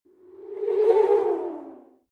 Spooky Ghost Sound Button: Unblocked Meme Soundboard
Spooky Ghost Sound Effects